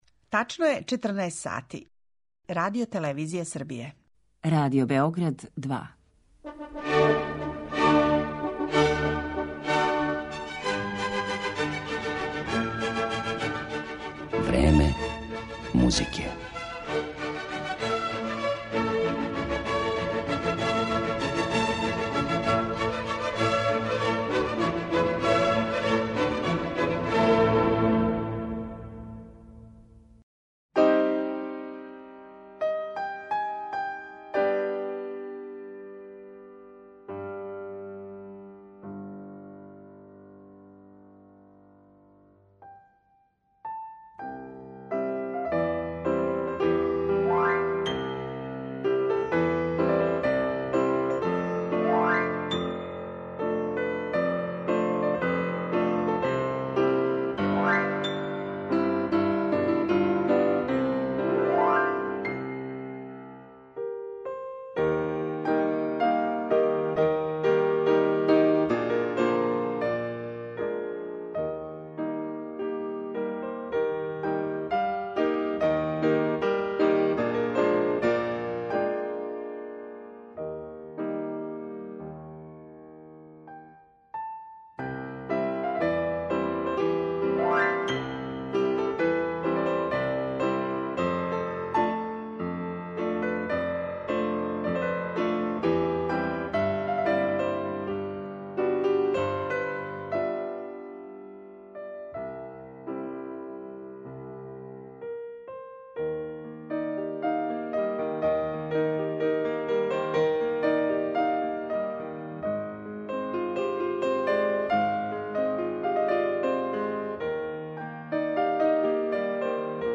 клавирска музика и соло-песме